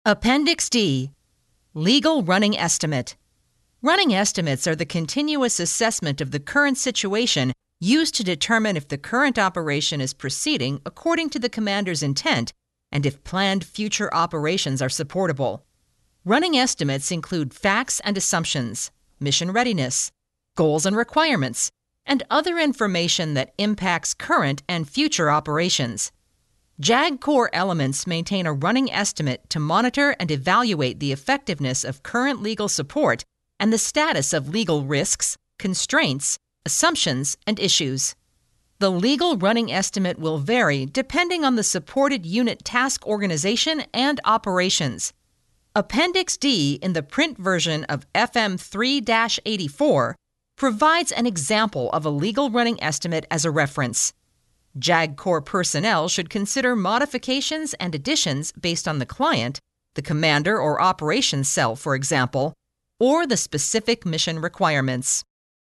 Army Doctrine Audiobook Download Page
It has been abridged to meet the requirements of the audiobook format.